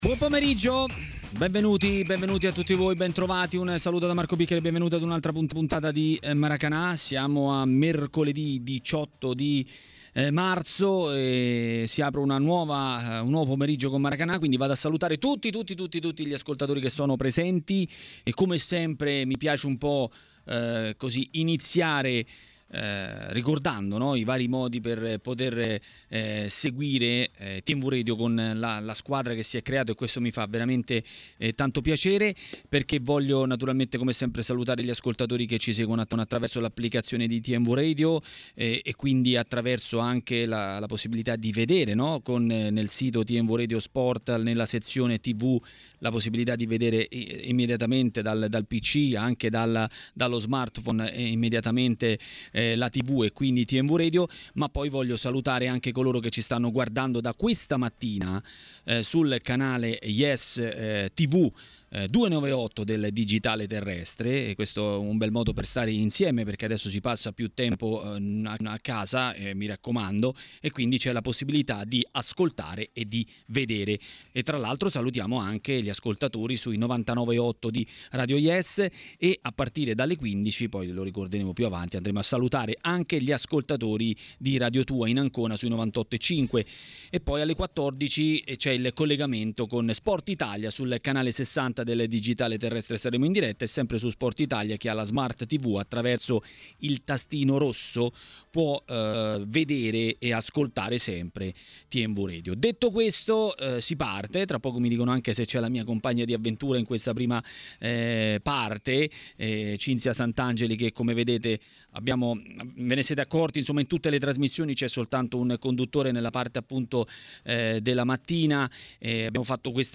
A dirlo è il direttore Italo Cucci in diretta a Maracanà, nel pomeriggio di TMW Radio, dopo le ultime decisioni della Uefa.